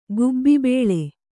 ♪ gubbi bēḷe